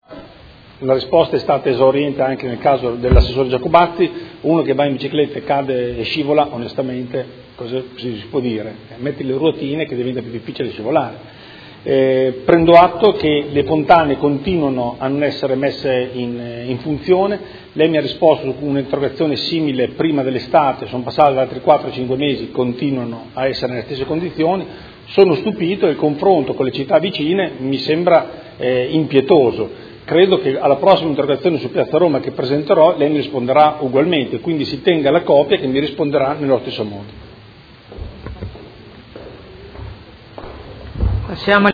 Andrea Galli — Sito Audio Consiglio Comunale
Seduta del 27/10/2016 Replica a risposta assessore. Interrogazione del Consigliere Galli (F.I.) avente per oggetto: Piazza Roma, ai malfunzionamenti si aggiungono gli incidenti?